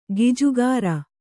♪ gijugāra